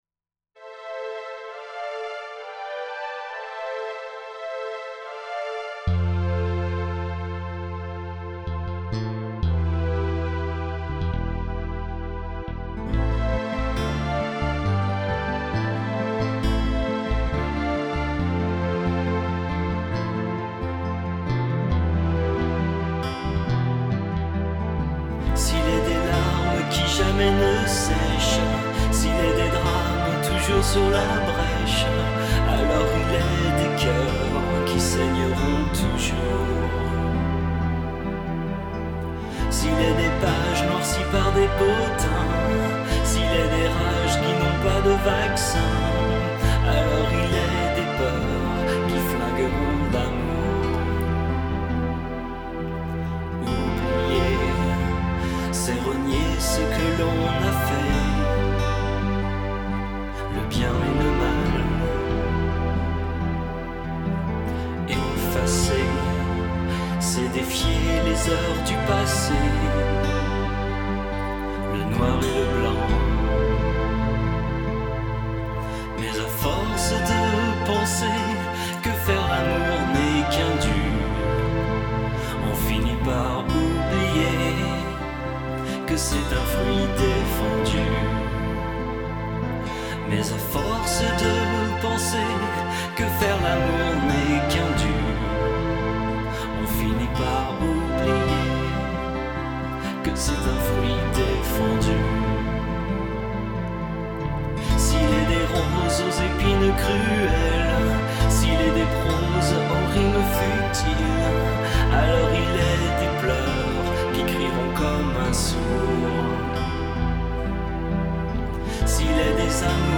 Here are the full (for fast internet connexions...) demo mp3 versions of these two songs... :